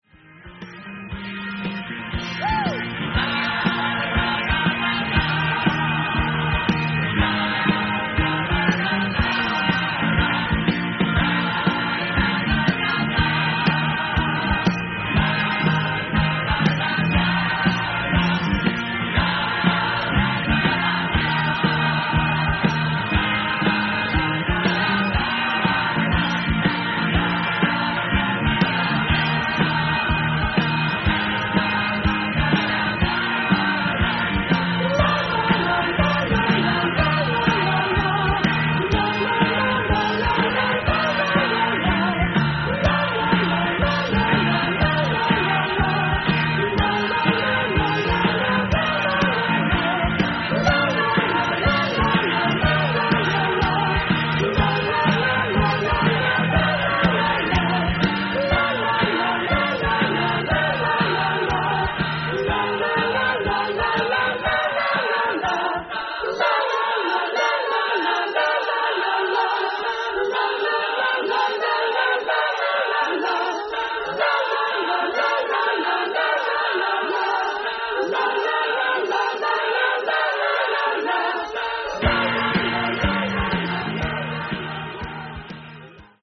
Musicland / Munich, Germany
Organ, Guitars & Vocals
Drums